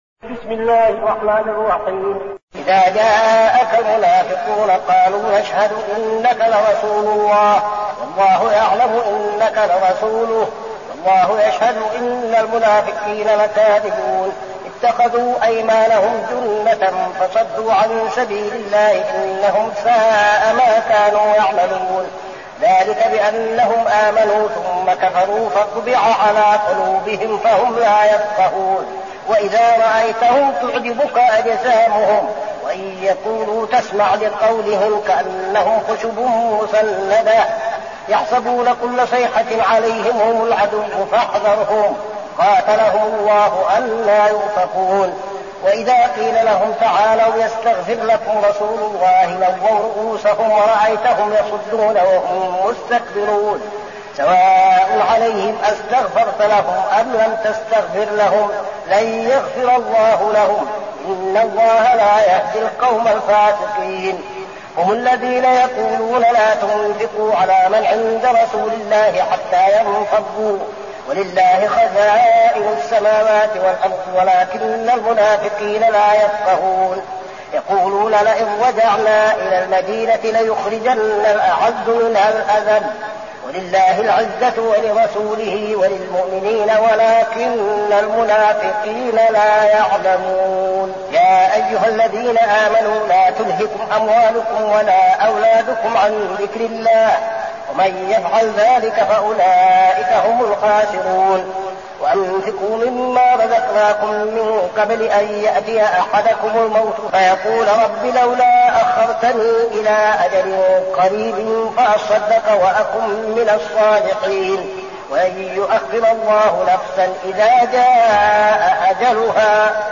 المكان: المسجد النبوي الشيخ: فضيلة الشيخ عبدالعزيز بن صالح فضيلة الشيخ عبدالعزيز بن صالح المنافقون